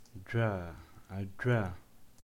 labzd voiced palato-alveolar affricate
[d͡ʒʷ] Abaza, Aghul, Tsakhur, German
Labialized_voiced_palato-alveolar_affricate.ogg.mp3